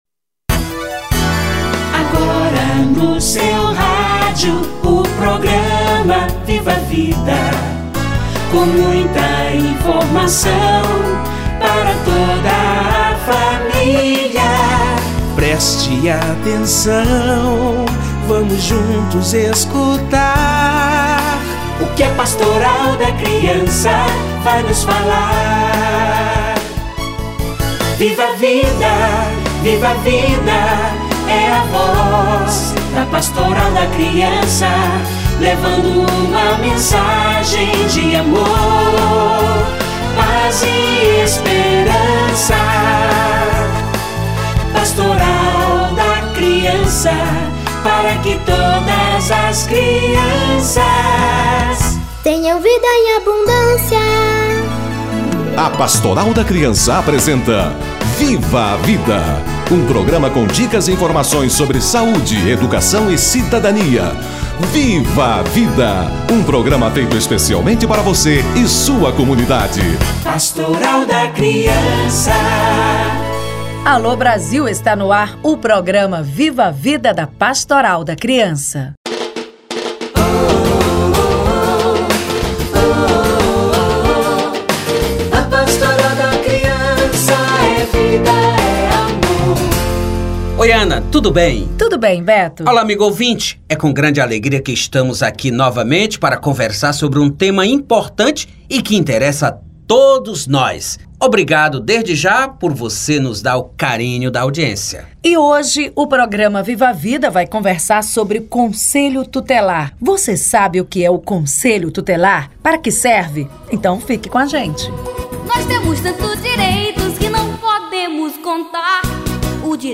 Conselho Tutelar - Entrevista